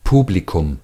Ääntäminen
IPA : /kɹaʊd/